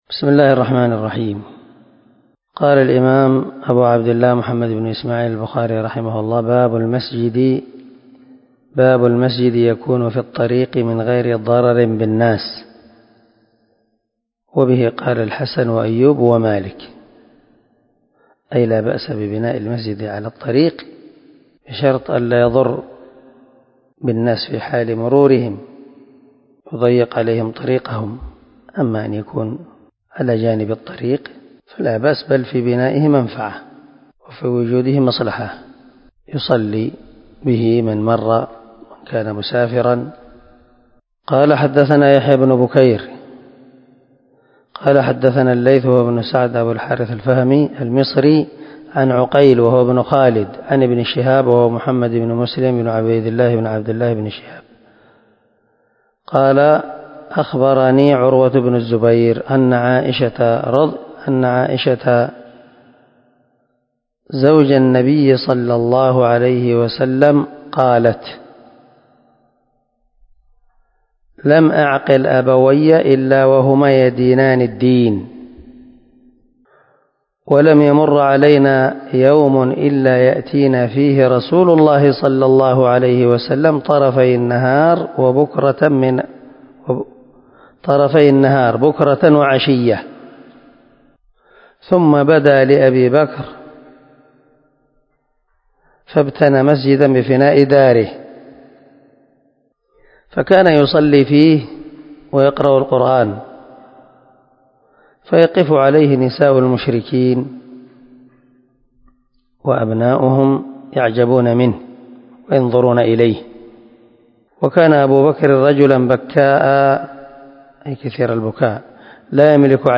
351الدرس 84 من شرح كتاب الصلاة حديث رقم ( 476 ) من صحيح البخاري